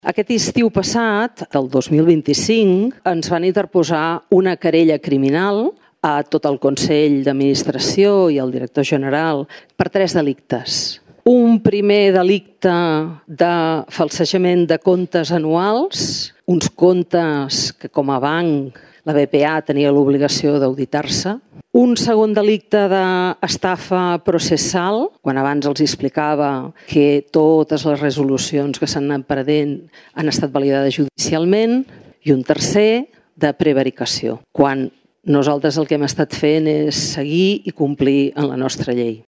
Ho ha assegurat La presidenta de l’Agència Estatal de Resolució d’Entitats Bancàries, Sílvia Cunill  durant la seva compareixença davant la comissió legislativa de Finances del Consell General, on ha fet balanç de l’estat del procés de liquidació concursal de l’entitat.